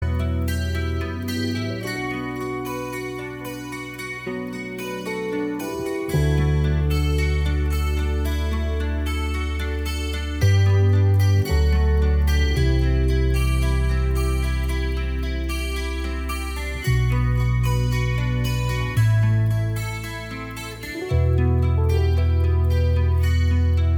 No Lead Guitar Rock 4:39 Buy £1.50